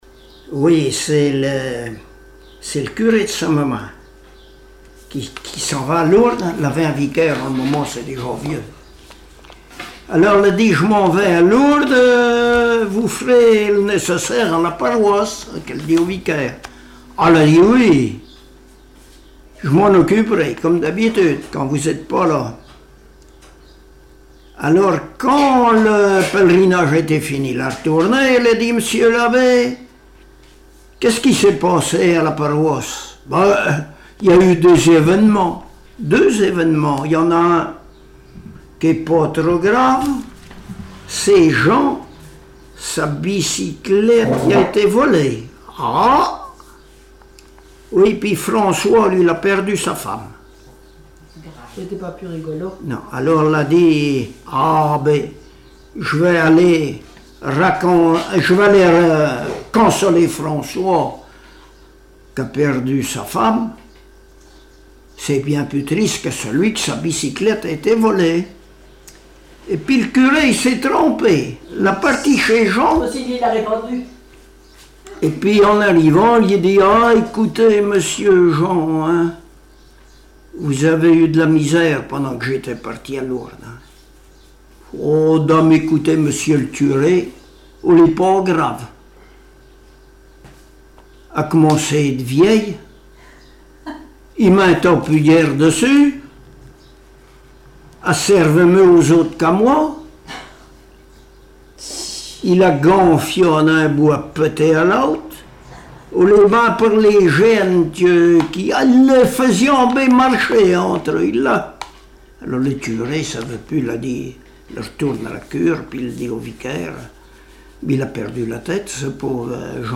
Genre sketch
Enquête Arexcpo en Vendée-Association Joyeux Vendéens
Catégorie Récit